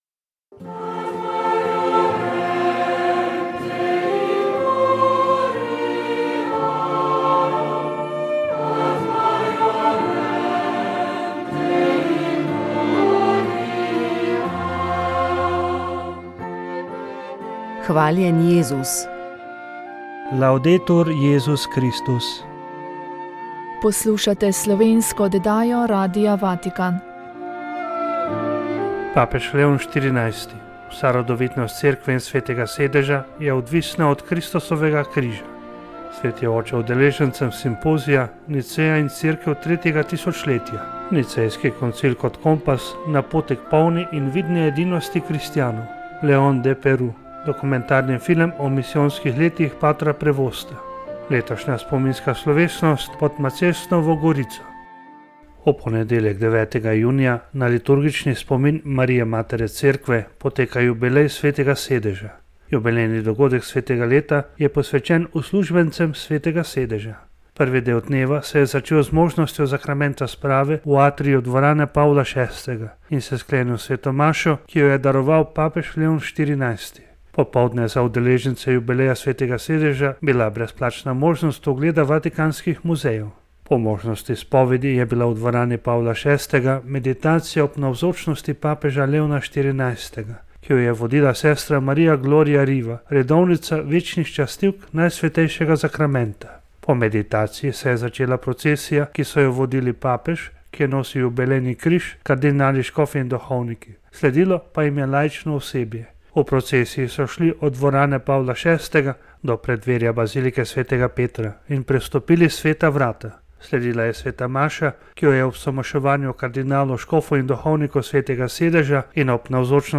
Poklicali smo Frančiškanko Brezmadežnega spočetja